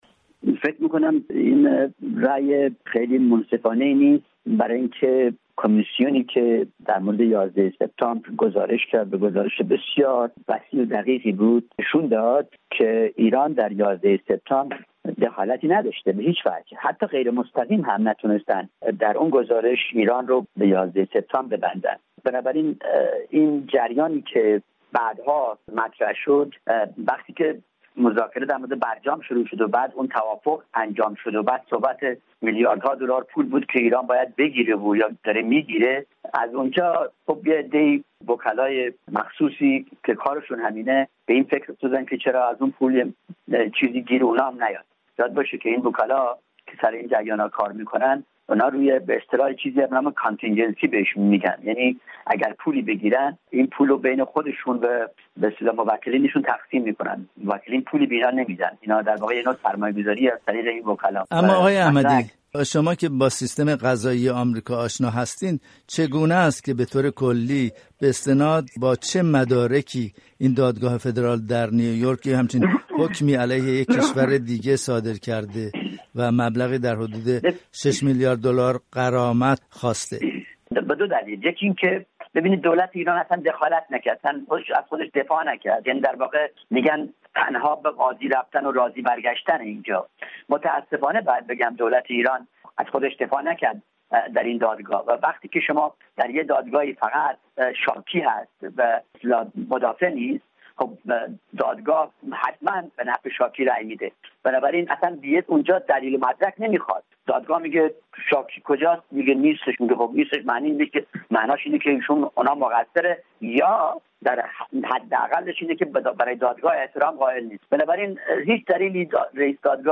گفت‌وگو با هوشنگ امیراحمدی، رئیس شورای ایران- آمریکا، درباره حکم پرداخت غرامت به آمریکایی‌ها